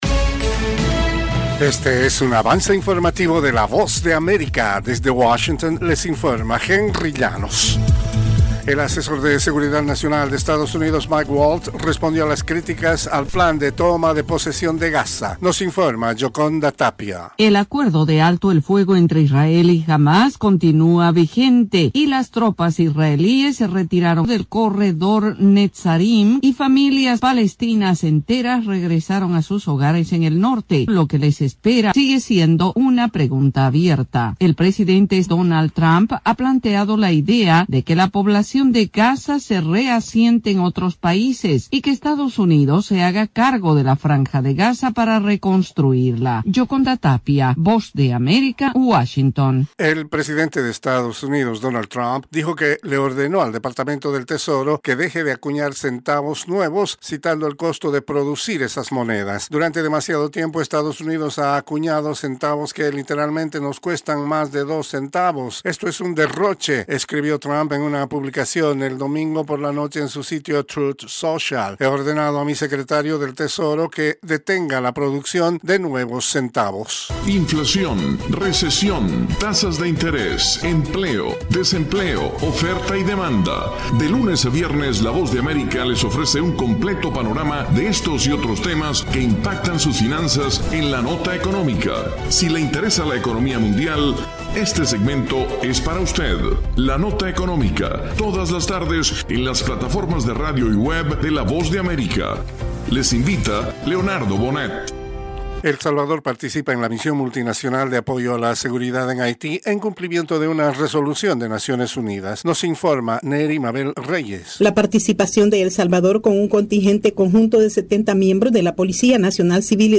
Cápsula informativa de tres minutos con el acontecer noticioso de Estados Unidos y el mundo.
Desde los estudios de la Voz de América en Washington